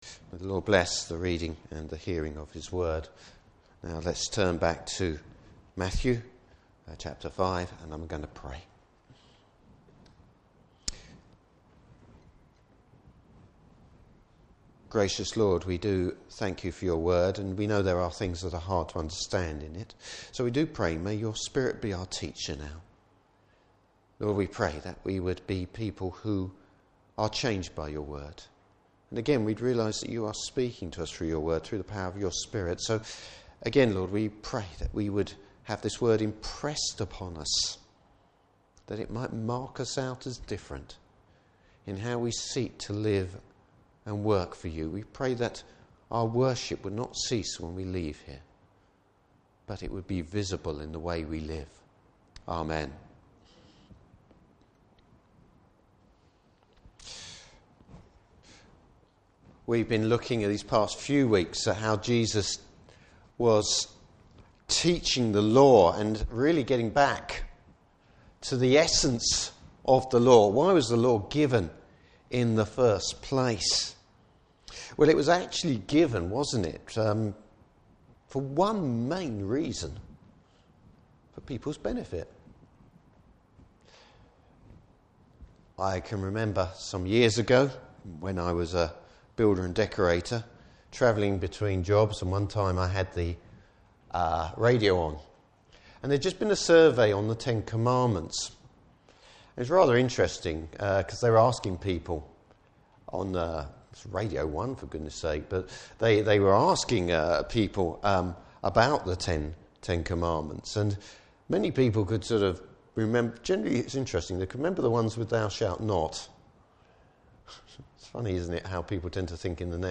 Service Type: Morning Service Bible Text: Matthew 5:31-48.